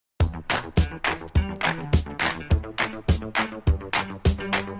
roulette-spin-ds.mp3